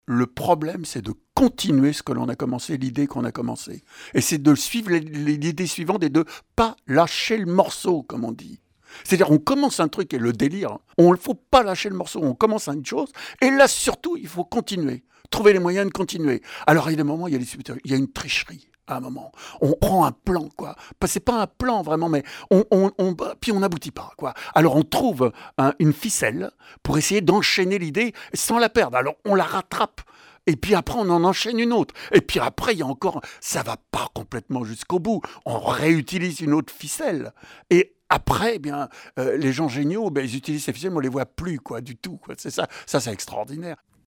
Extraits de l’émission Black & Blue d’Alain Gerber sur France Culture